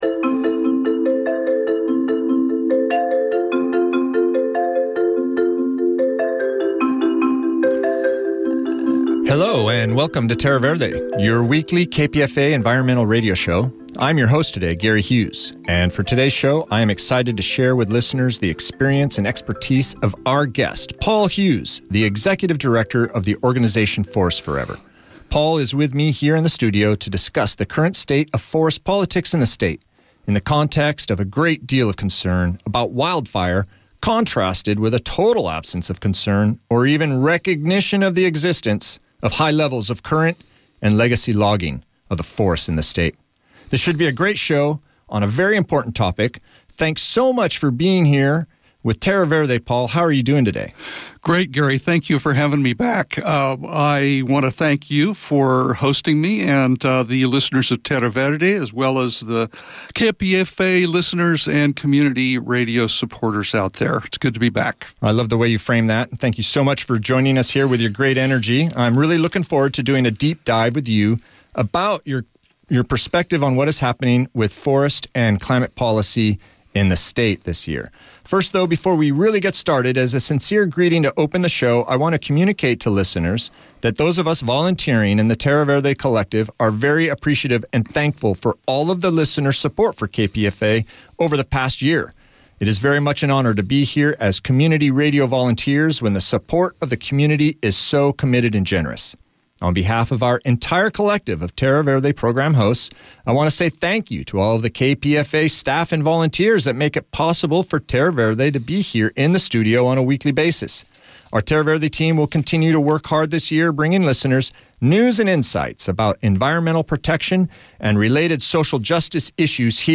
A weekly public affairs show that delivers news and views about the most critical environmental issues across California and globally.